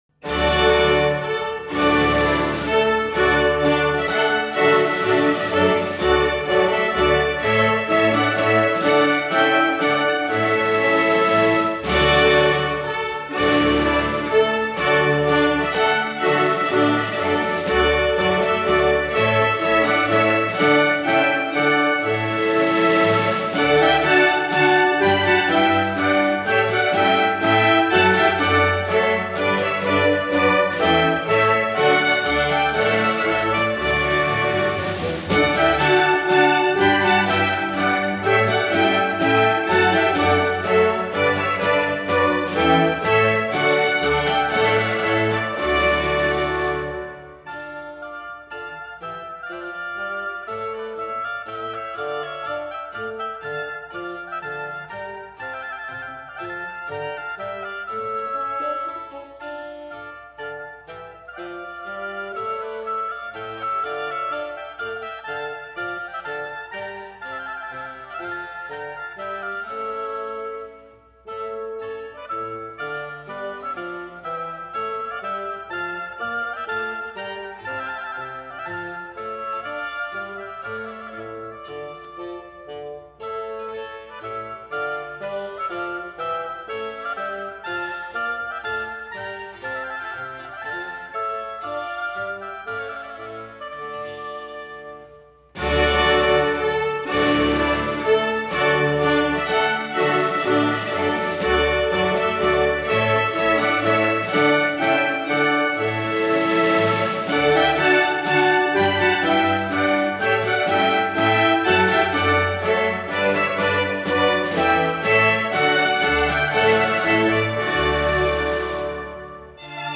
副题是“西西里风格的广板”。
主题采用广板，悠长的旋律，柔和温存，是巴洛克时代的田园曲。